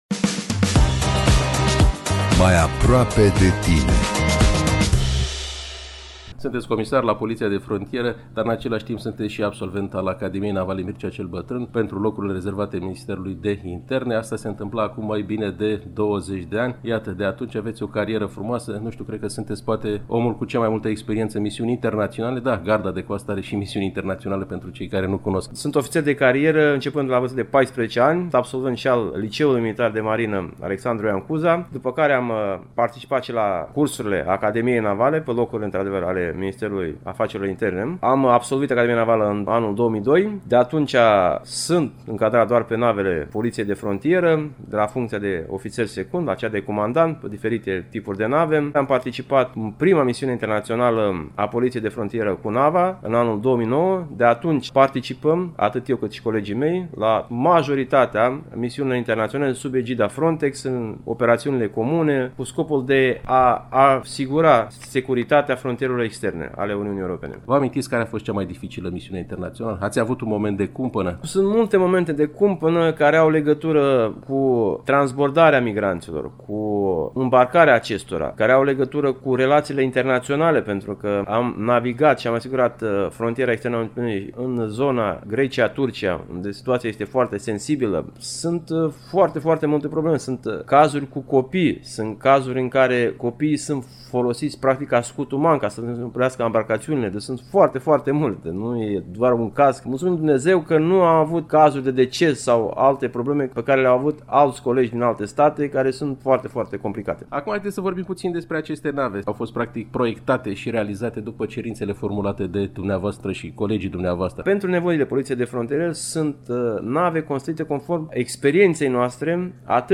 Interviul integral, în format video, îl găsiți pe pagina de Facebook „Jurnal militar” Constanța.